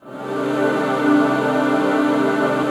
06PAD 01  -L.wav